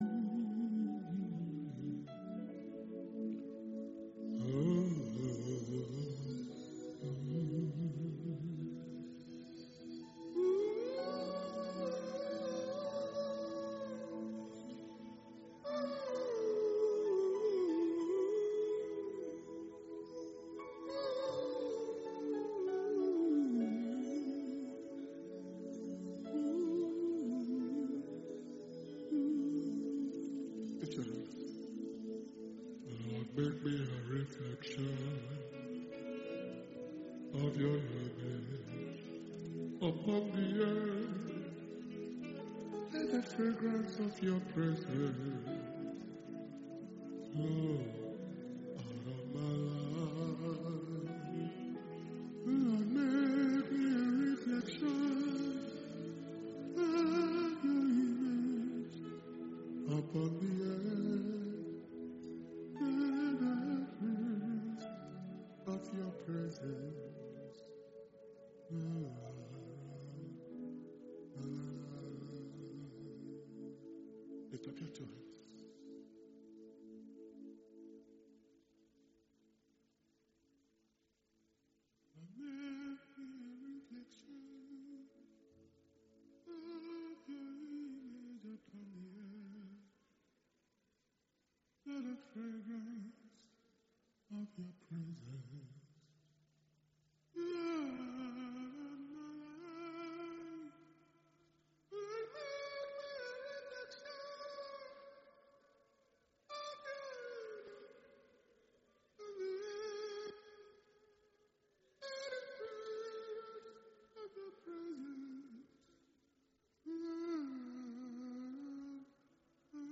2021 February Blessing Sunday Service message